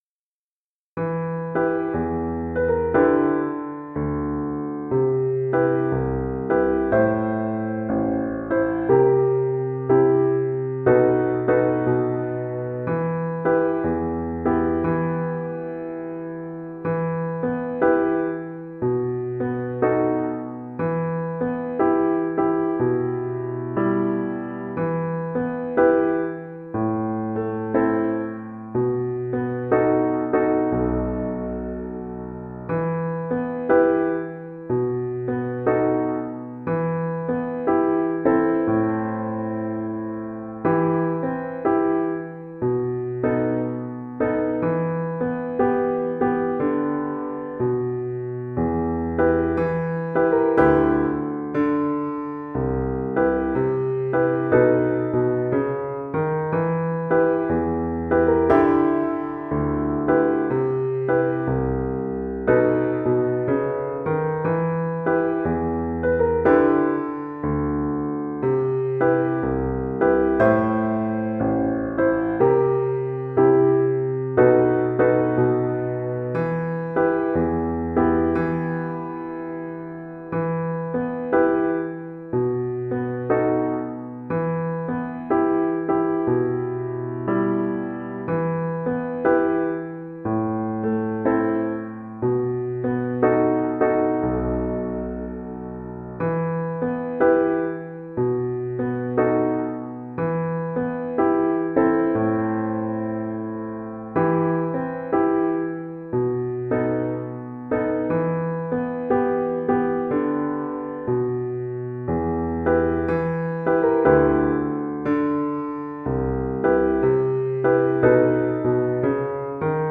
Soprano (Descant) Recorder
This famous Blues melody has its origins in the 18th century English folk ballad The Unfortunate Rake.
4/4 (View more 4/4 Music)
Medium Swing = 96
Arrangement for Descant Recorder and Piano
E minor (Sounding Pitch) (View more E minor Music for Recorder )
jazz (View more jazz Recorder Music)